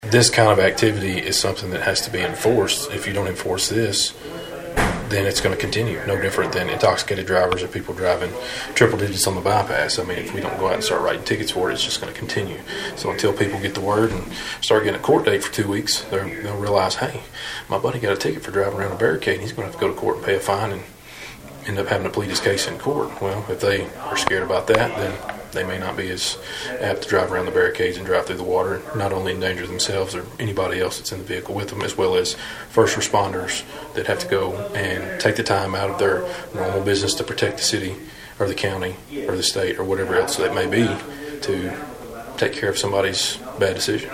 More than a dozen of Trigg County’s lead officials gathered Monday morning at the Emergency Operations Center on Jefferson Street, and all of them had the same message to send to the community.